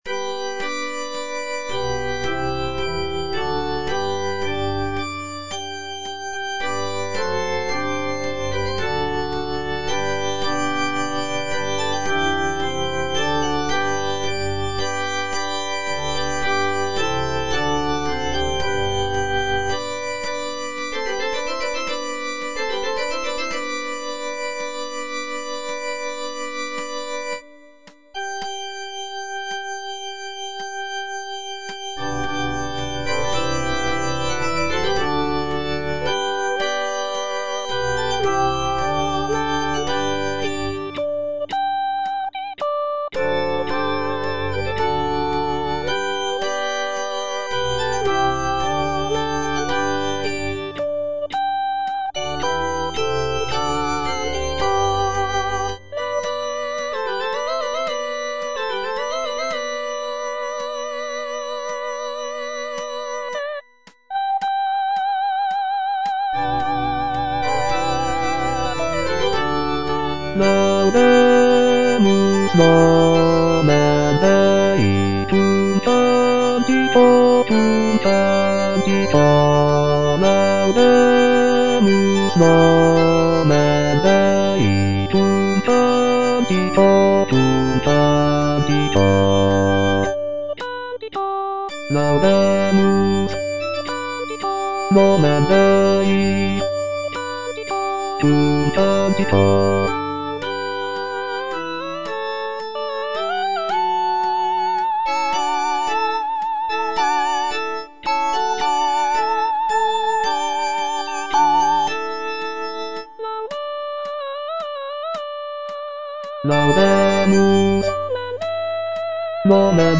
J.P. RAMEAU - IN CONVERTENDO Laudate nomen Dei (bass) (Voice with metronome) Ads stop: auto-stop
"In convertendo" is a sacred motet composed by Jean-Philippe Rameau in the 18th century. The piece is written for four voices and is based on a psalm text.